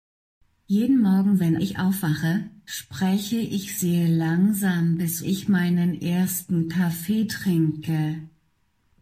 Hörbeispiel: AWS Polly - Vicki mit SSML(x-slow).mp3